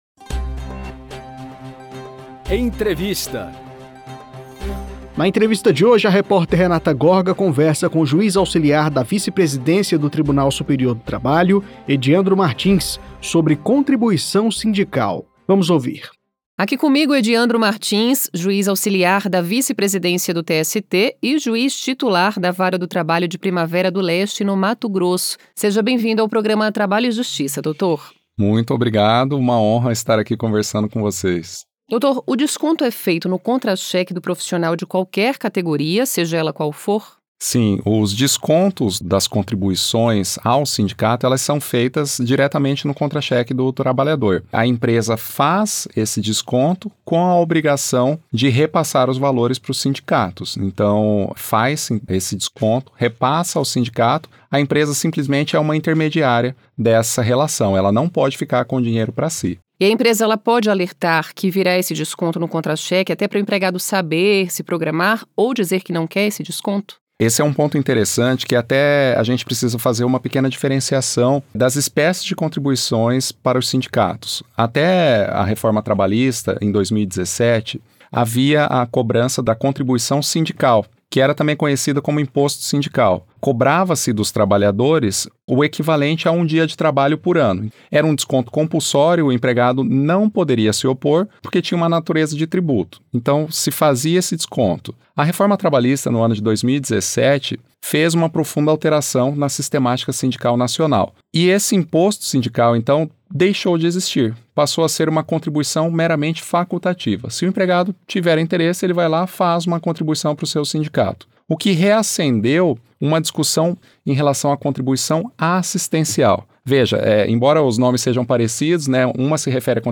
Em entrevista, juiz Ediandro Martins, auxiliar da Vice-Presidência do TST, fala sobre o direito do empregado de se opor ao desconto